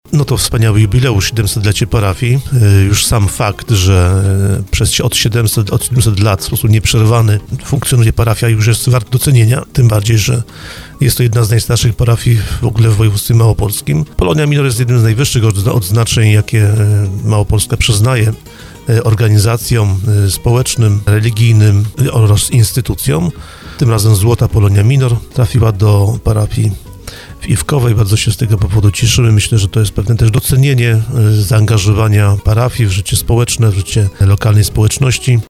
mówił Wojciech Skruch wiceprzewodniczący Sejmiku Województwa Małopolskiego.